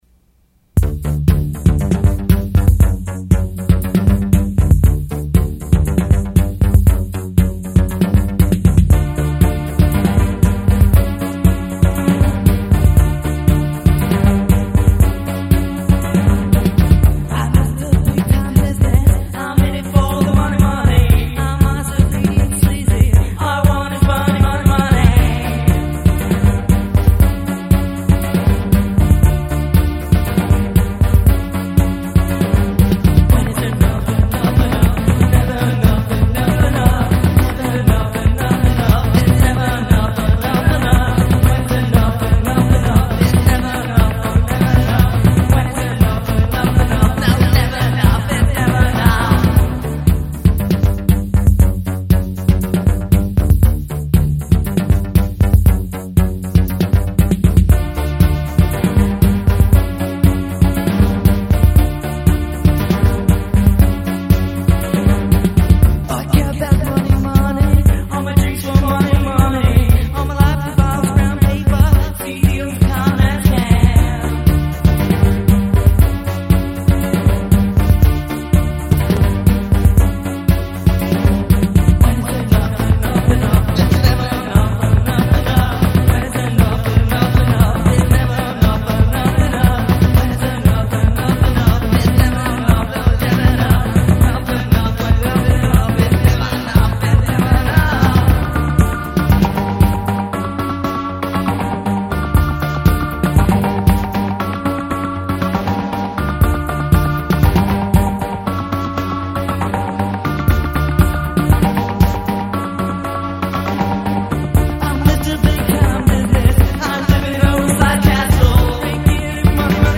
-Upbeat Song Clips-